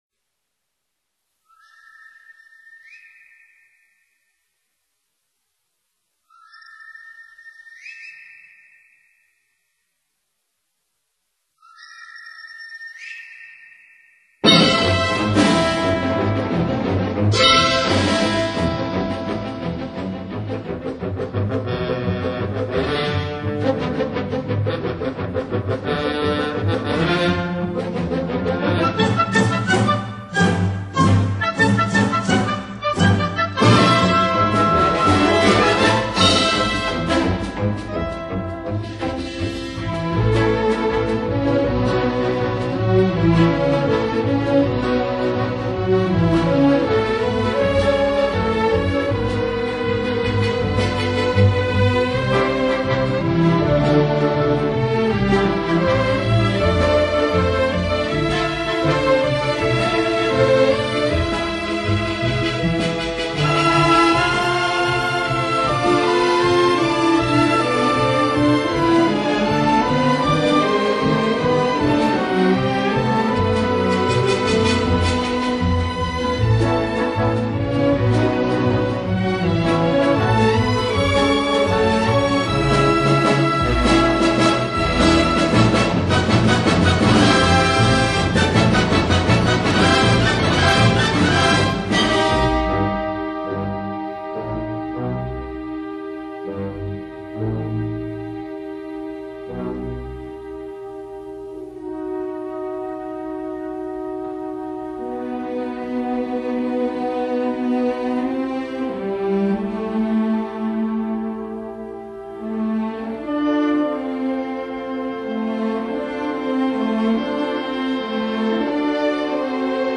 light]美国音乐剧
[mjh4][light]那歌声...那旋律...悠扬飘荡...[/light][/mjh4]